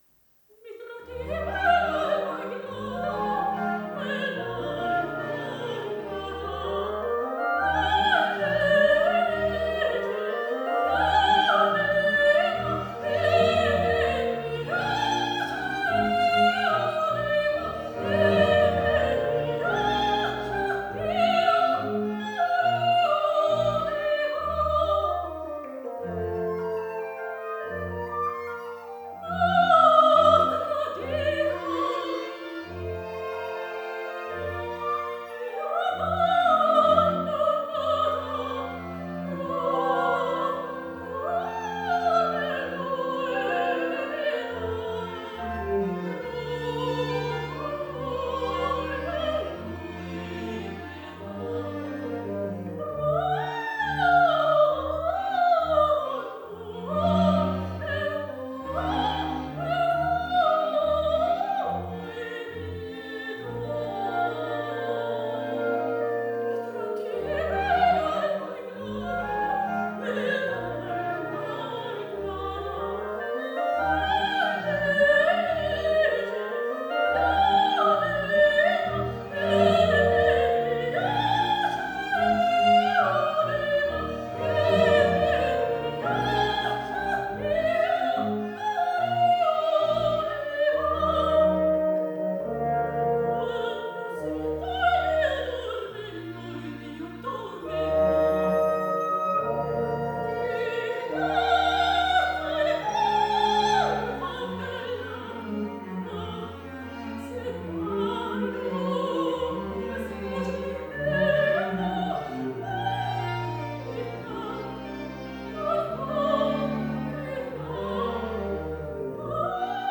MOZART_HolyTrinity_FreqDomainP1.wav